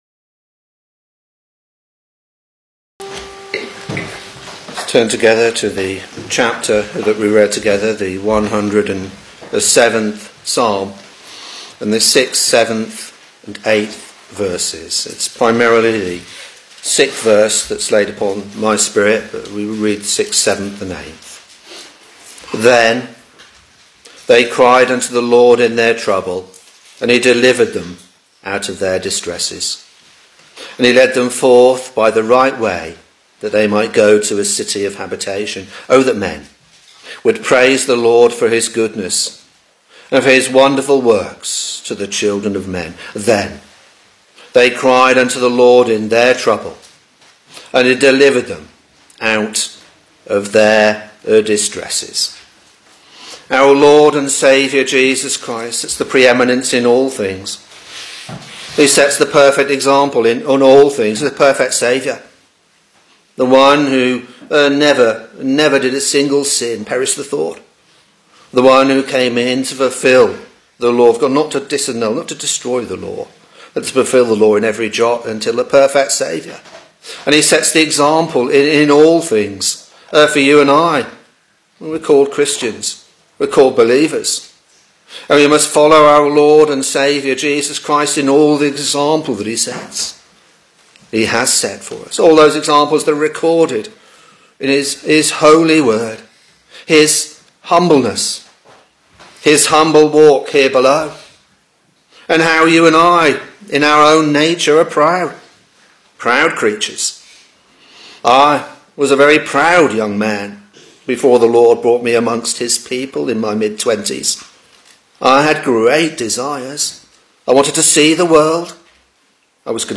Sermons Psalm 107 v.6, v.7 & v.8 (particularly v.6)